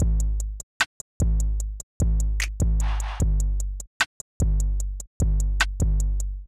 Drum Loop (R.I.P. SCREW).wav